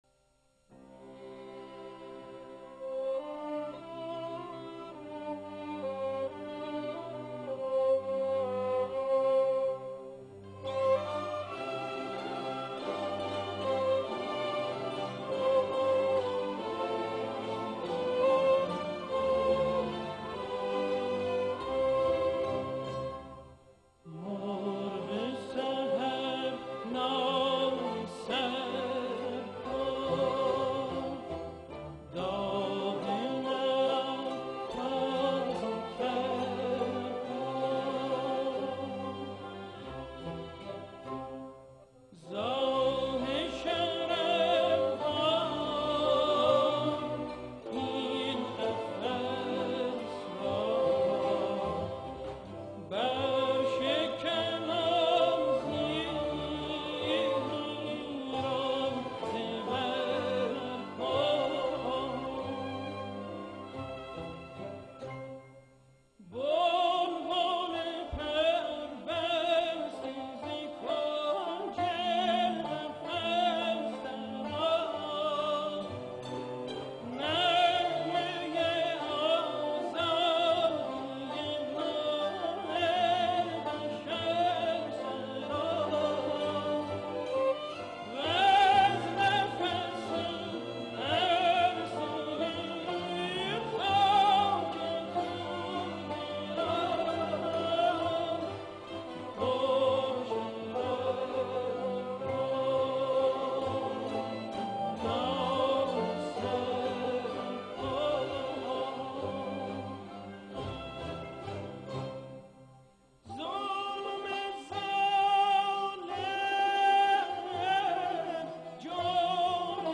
Audio1  sung
the orchestra may enhance the enjoyment of the song